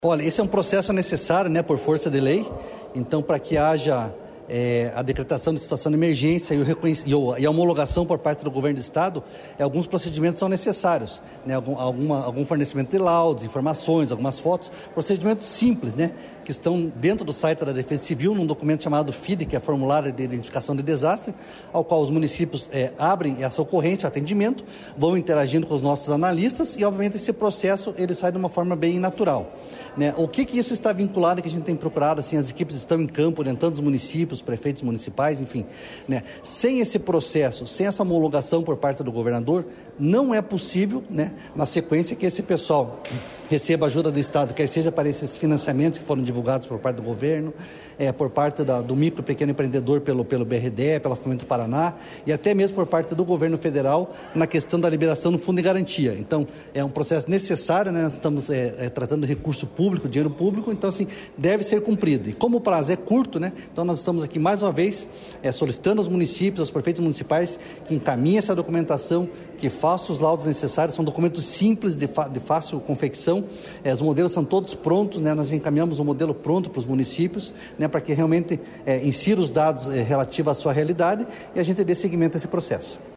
Sonora do coordenador estadual da Defesa Civil, coronel Fernando Schünig, sobre orientação para os pedidos emergenciais